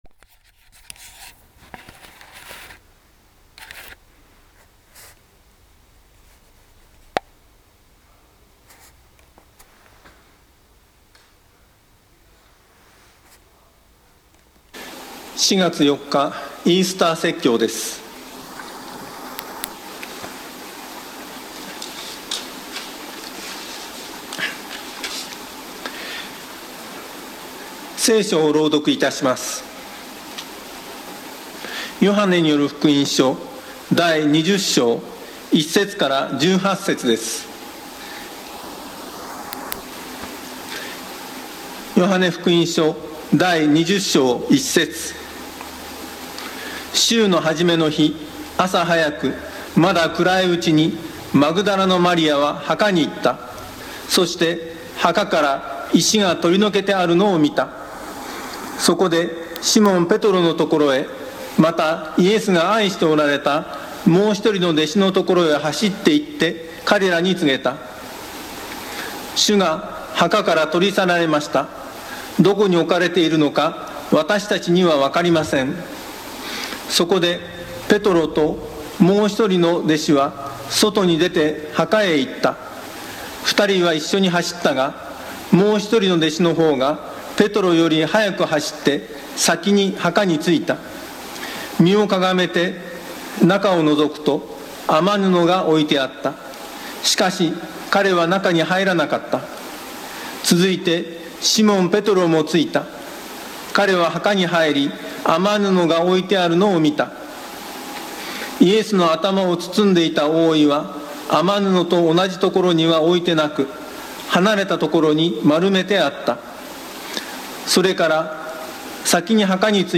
Youtubeで直接視聴する 音声ファイル 礼拝説教を録音した音声ファイルを公開しています。